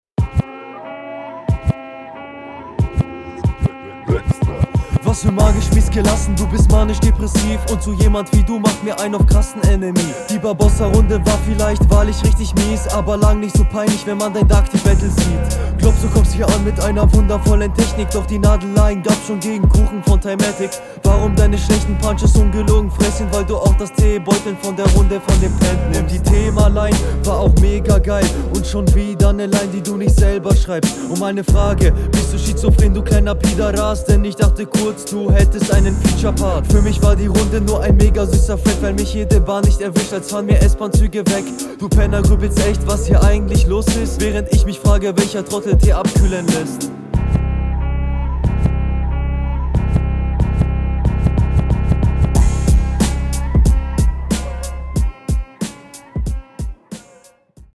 Kommst nicht ganz so gut auf den Beat wie dein Gegner.